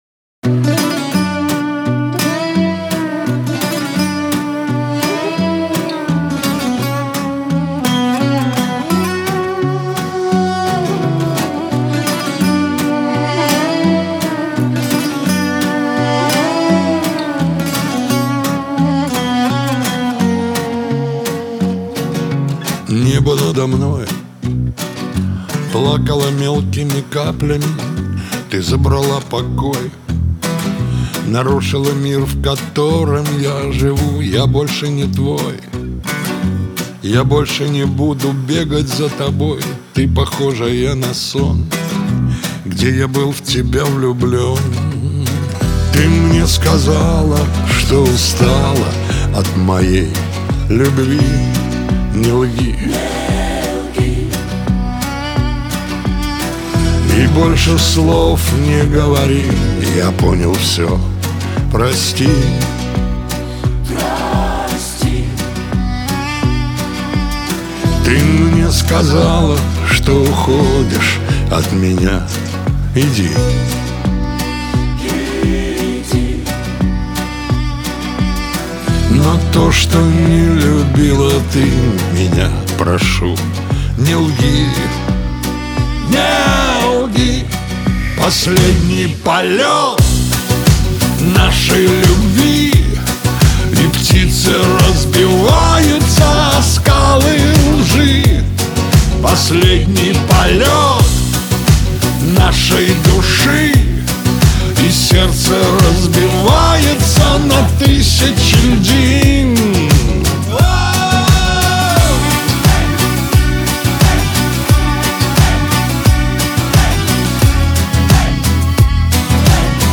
диско , Шансон
эстрада